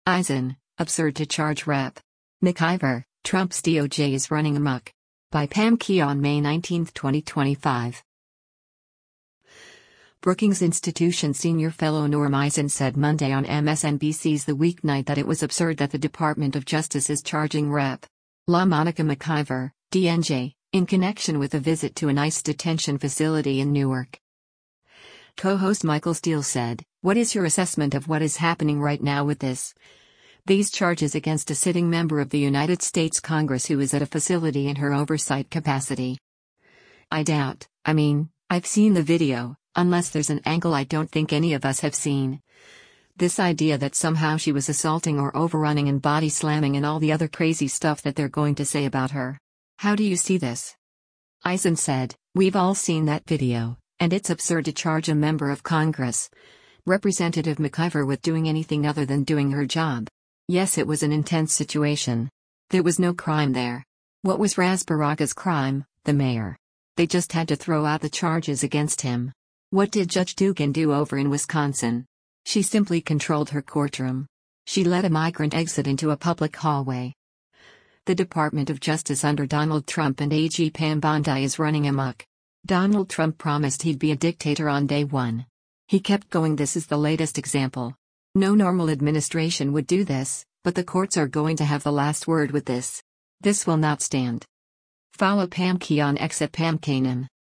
Brookings Institution senior fellow Norm Eisen said Monday on MSNBC’s “The Weeknight” that it was “absurd” that the Department of Justice is charging Rep. LaMonica McIver (D-NJ) in connection with a visit to an ICE detention facility in Newark.